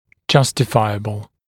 [‘ʤʌstɪfaɪəbl][‘джастифайэбл]могущий быть оправданным, допустимый, позволительный